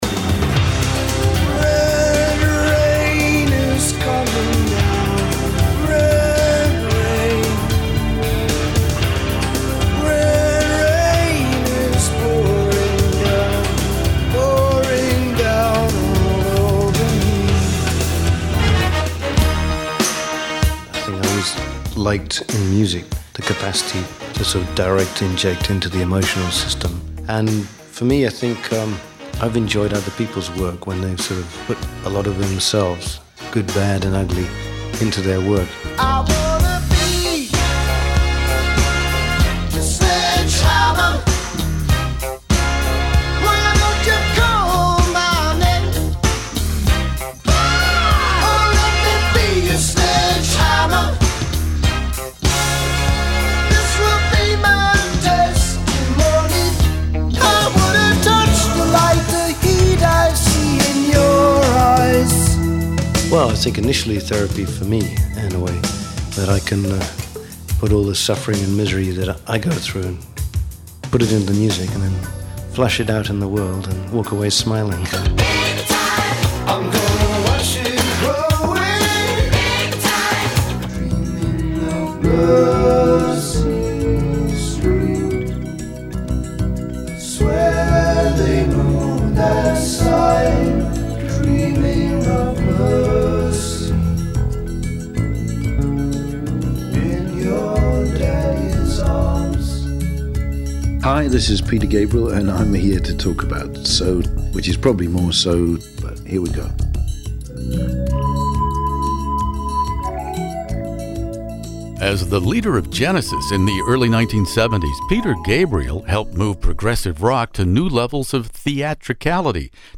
Peter Gabriel joins me In the Studio to discuss how the worldwide mainstream pop stardom which followed the release of So affected him in so many ways.